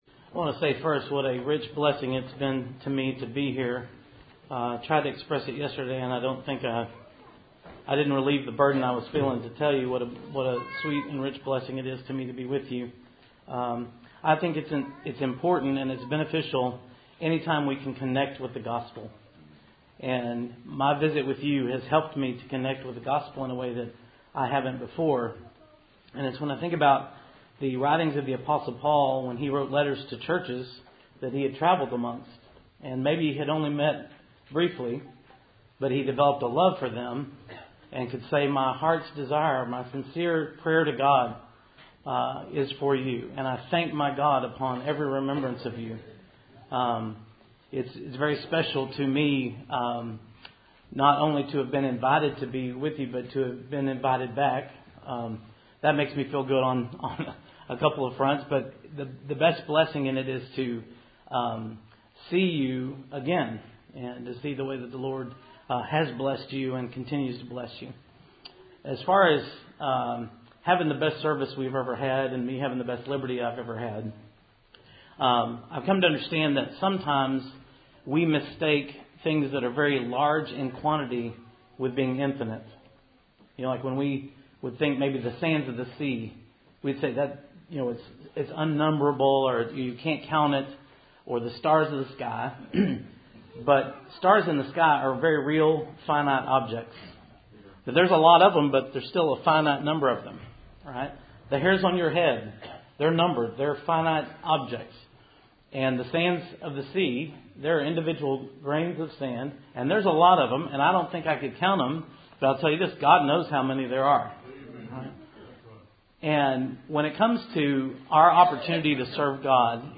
Service Type: Cool Springs PBC March Annual Meeting